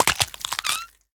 Minecraft Version Minecraft Version snapshot Latest Release | Latest Snapshot snapshot / assets / minecraft / sounds / mob / turtle / baby / egg_hatched3.ogg Compare With Compare With Latest Release | Latest Snapshot
egg_hatched3.ogg